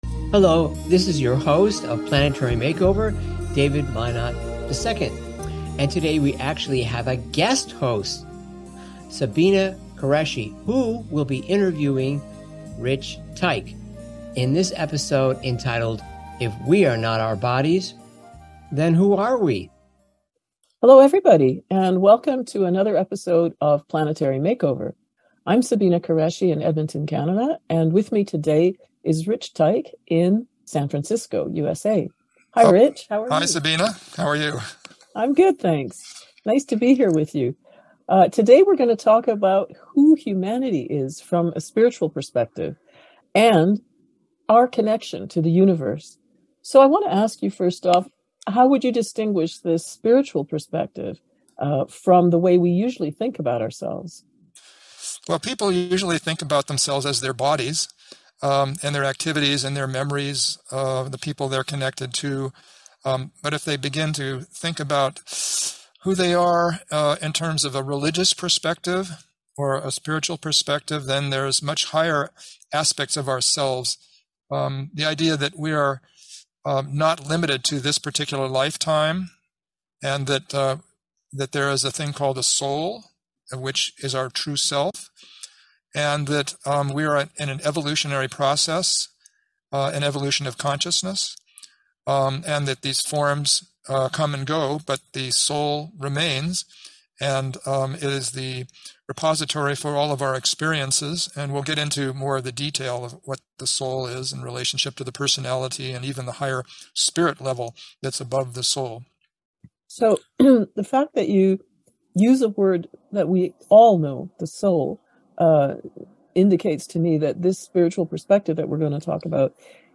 Talk Show Episode, Audio Podcast, Planetary MakeOver Show and If We Are Not Our Bodies, Then Who Are We?